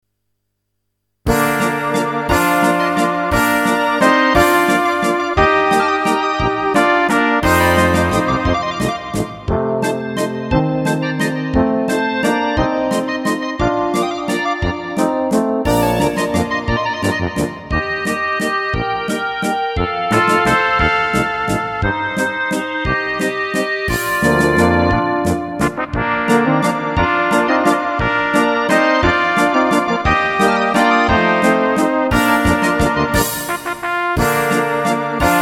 Rubrika: Pop, rock, beat
- valčík
Karaoke